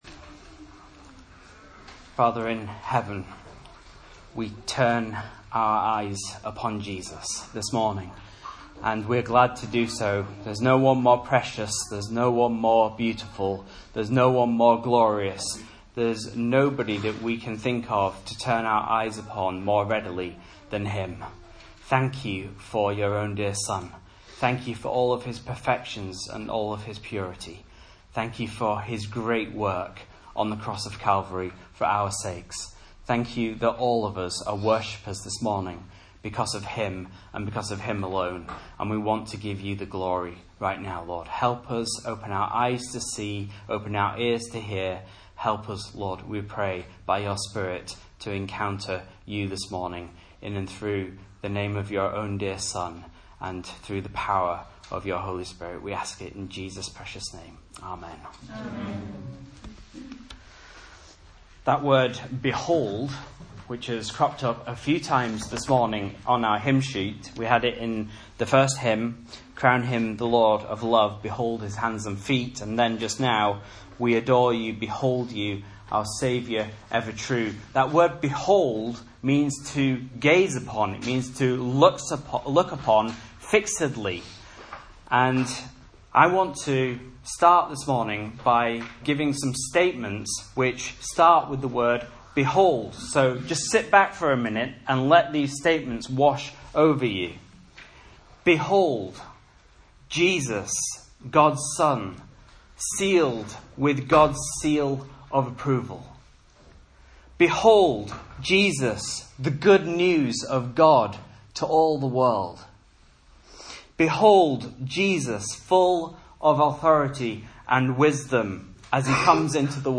Message Scripture: Mark 8:1-21 | Listen